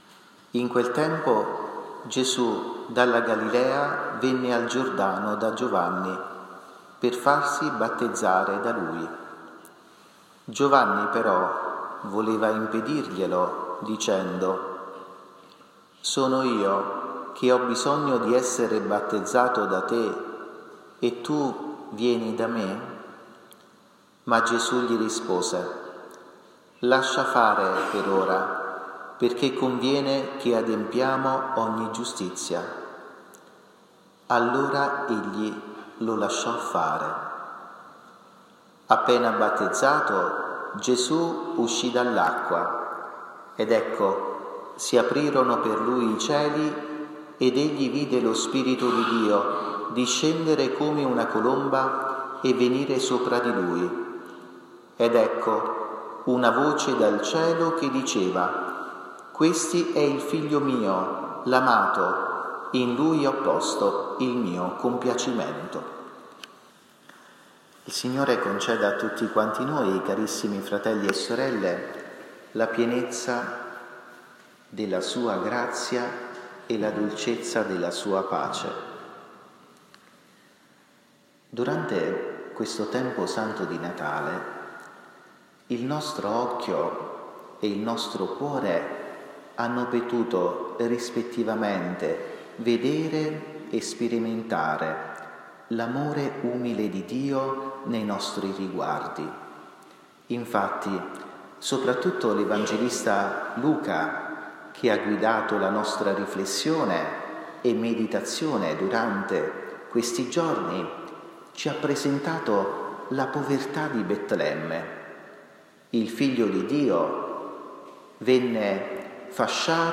omelia_8_gennaio_2023.mp3